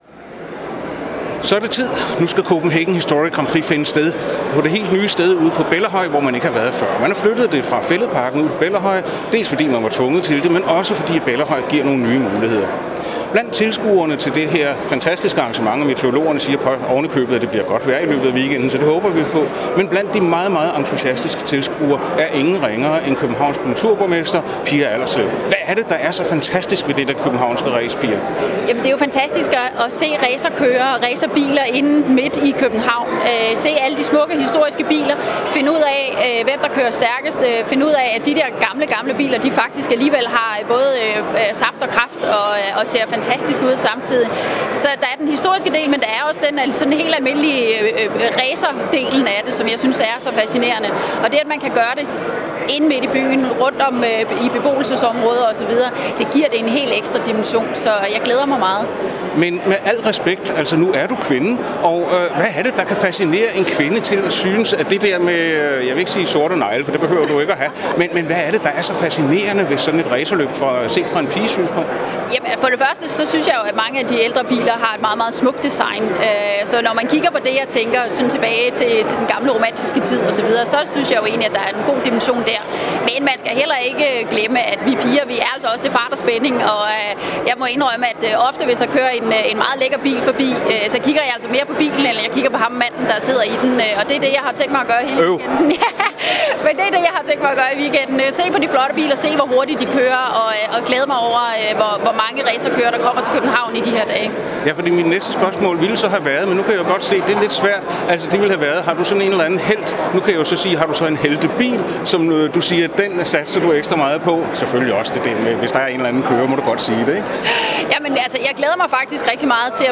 2013 CHGP interview Pia Allerslev og Jac Nellemann.mp3